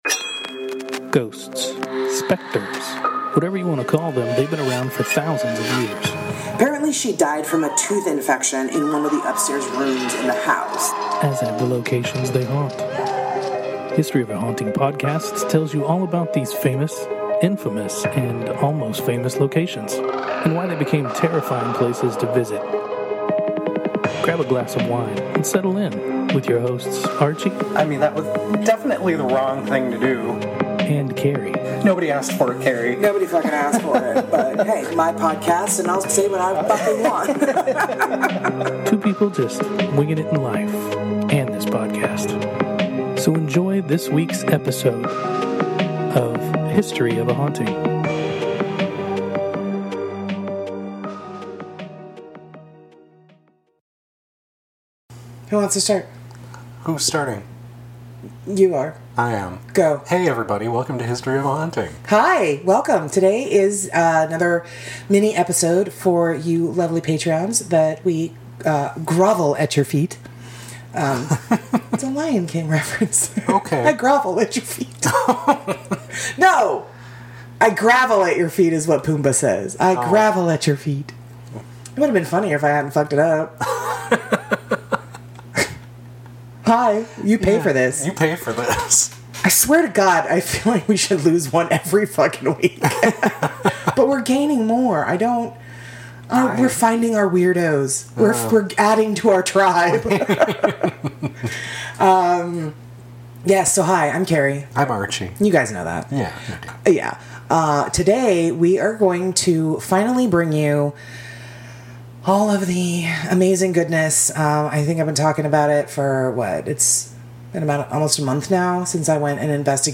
And two- because it’s the very first episode where we actually got to do a paranormal investigation of the locale and report our findings as well as the notorious hauntings it’s become famous for!